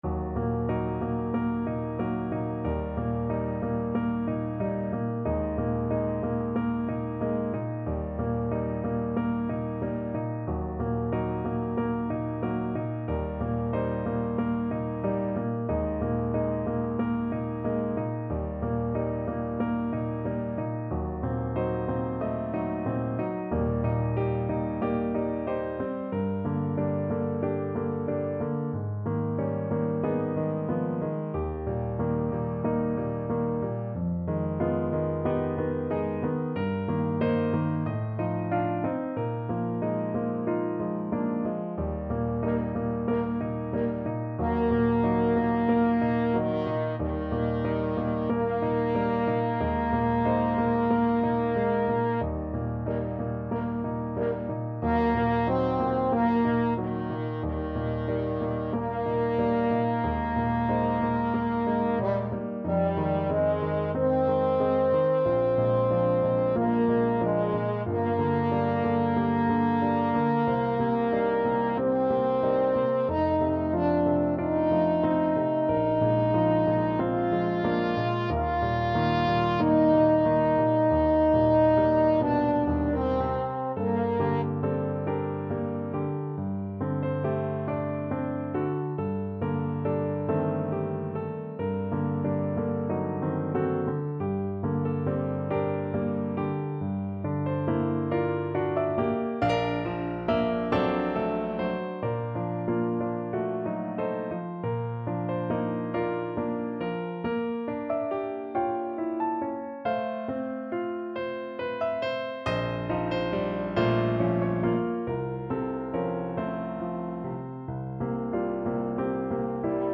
4/4 (View more 4/4 Music)
Andante (=46)
Bb3-Bb5
Classical (View more Classical French Horn Music)